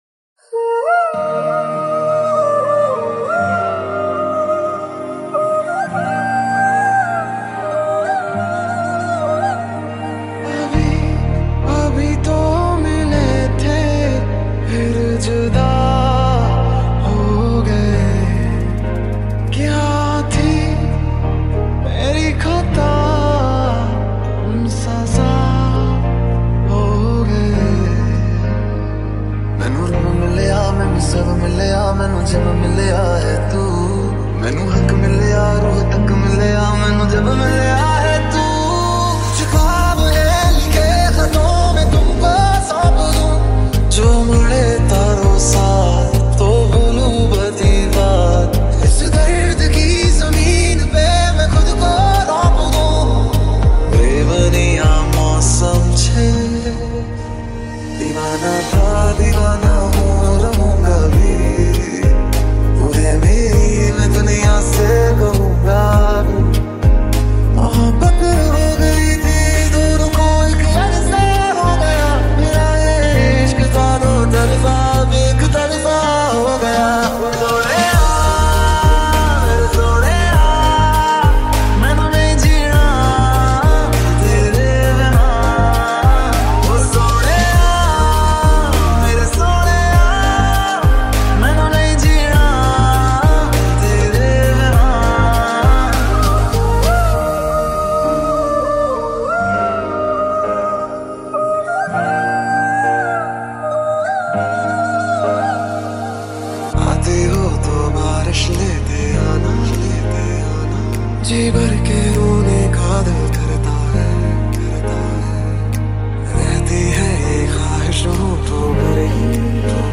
High quality Sri Lankan remix MP3 (3.7).
high quality remix